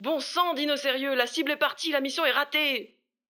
VO_ALL_EVENT_Temps ecoule_02.ogg